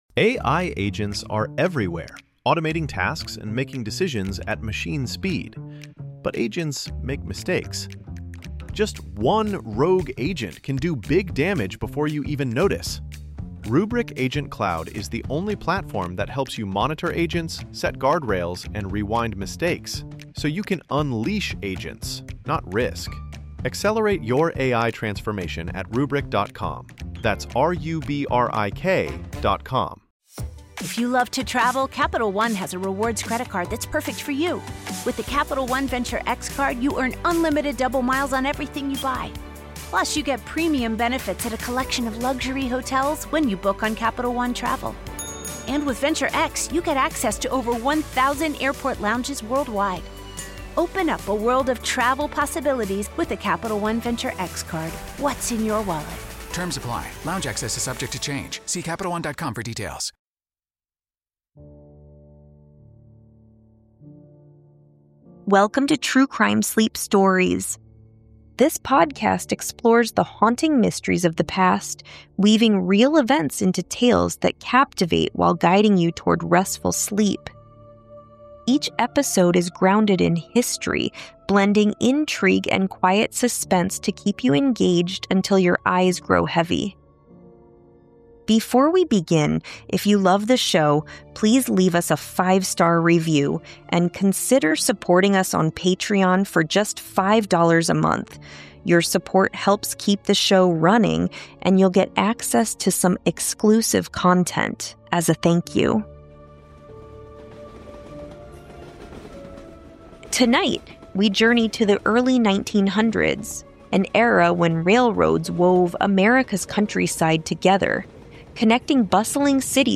From Trenton, South Carolina, to Villisca, Iowa, we explore the eerie patterns—covered mirrors, untouched valuables, and meals eaten in silent homes—that marked these unsolved crimes. Join us as we weave historical facts with a soothing narrative to guide you into restful sleep, while the mystery of the axe-wielding drifter lingers like mist over the rails.